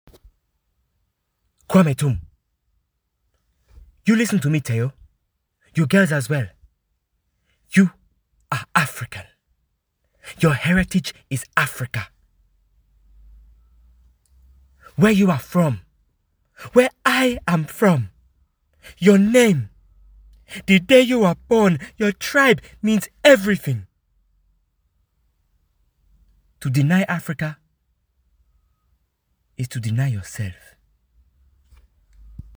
• Male
African accent demo